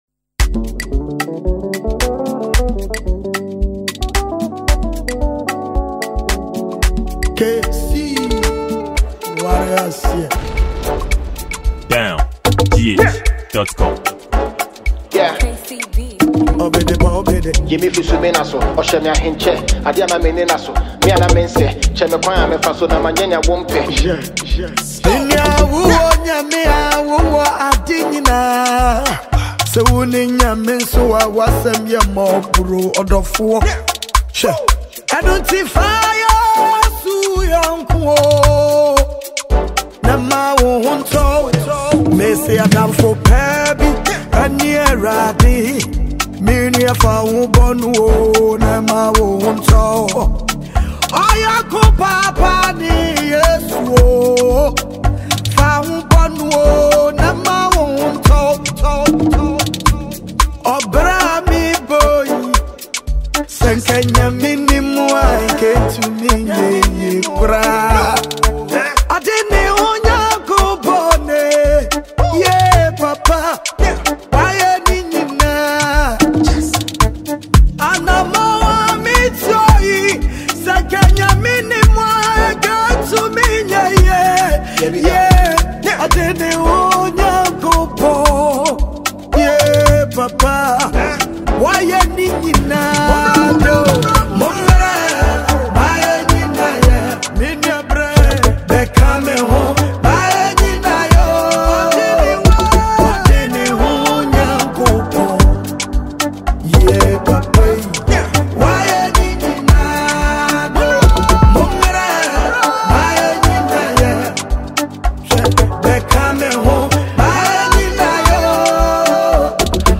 Ghana Music, Gospel
Renowned gospel singer
a Ghanaian multi-talented rapper.